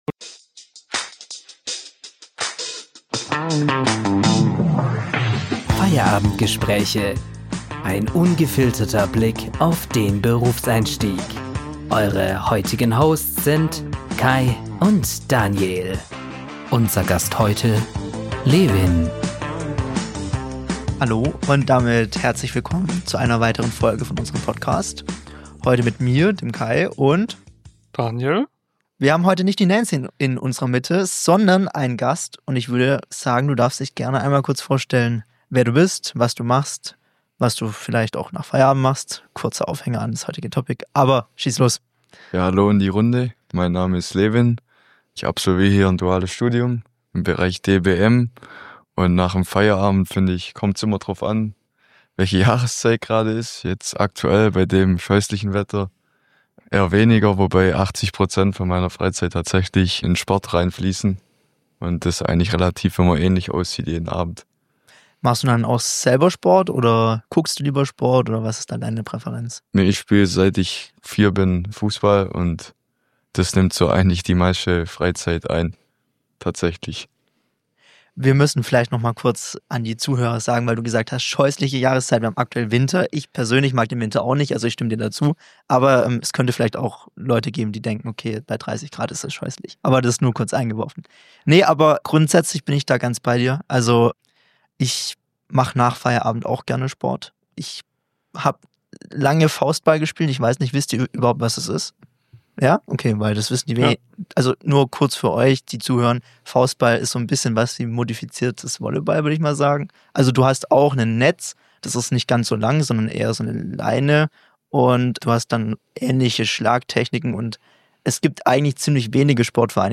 Gemeinsam tauschen sie sich aus, welche Hobbys sie nach dem Arbeitstag begeistern und überraschen mit ausgefallenen Freizeitideen.